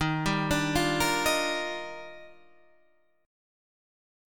D# Major 9th